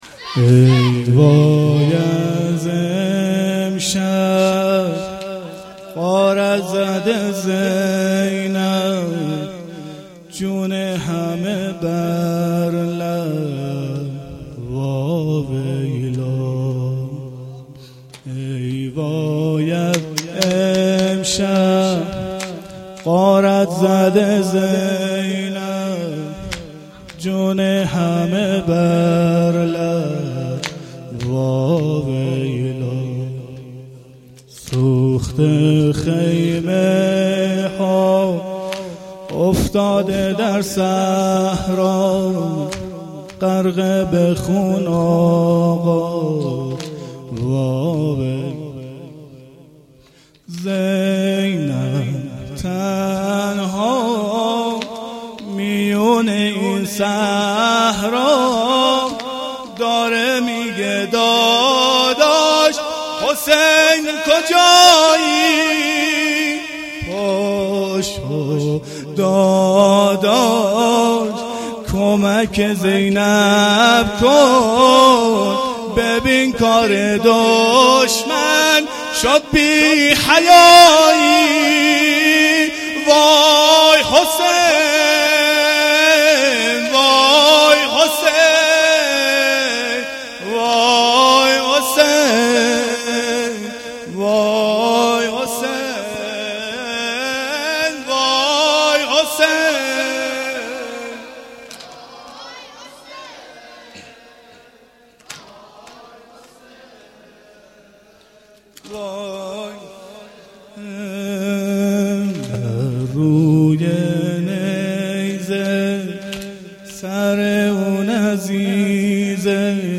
گزارش صوتی جلسه هفتگی 28 محرم الحرام